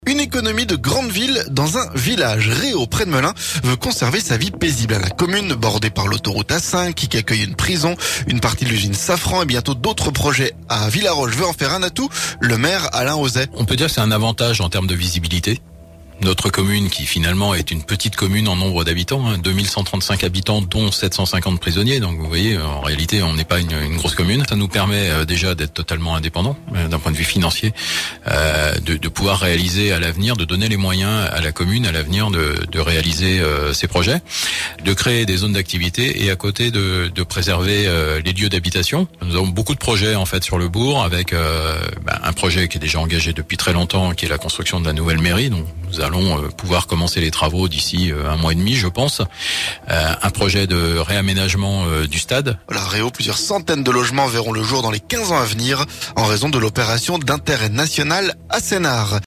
REAU - Entretien avec Alain Auzet, maire réélu